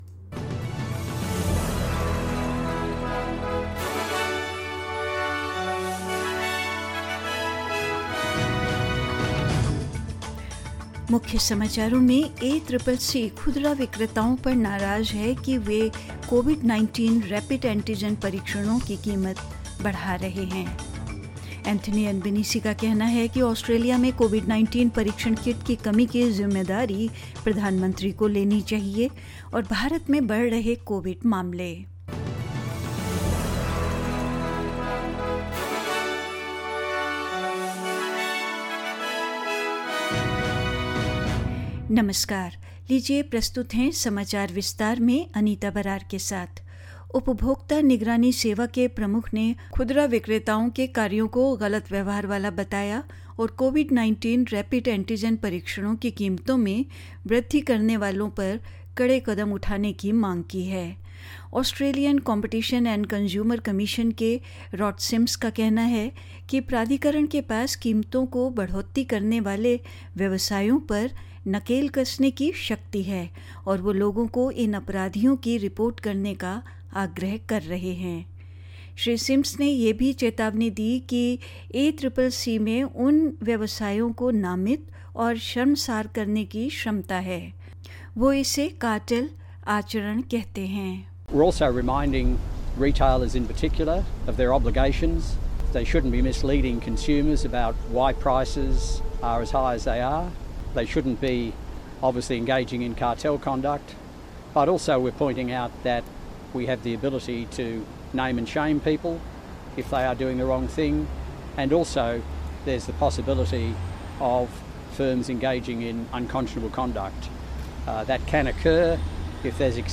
In this latest SBS Hindi news bulletin: The ACCC lashes out at retailers who it says are price gouging COVID-19 rapid antigen tests; Anthony Albanese says the Prime Minister should take responsibility for the shortage of Covid-19 testing kits in Australia; In India, Delhi CM Arvind Kejriwal tests positive for COVID -19 and more news.